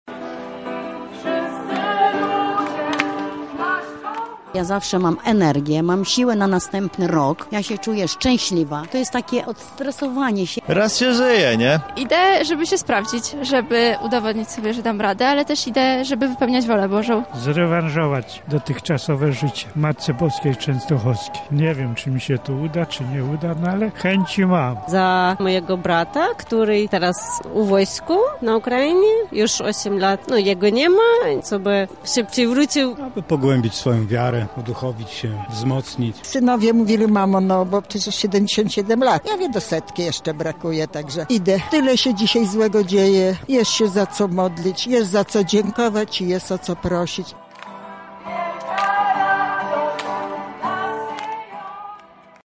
Zapytaliśmy ich dlaczego maszerują na Jasną Górę: